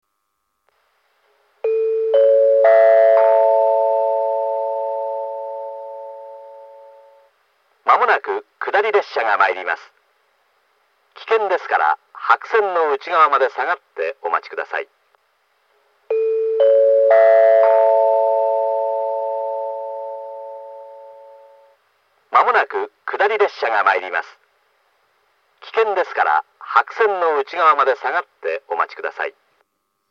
接近放送は接近表示機とホーム軽井沢寄りにあるスピーカーから、発車ベルは電子電鈴から流れます。
１番線接近放送
miyota-1bannsenn-sekkinn.mp3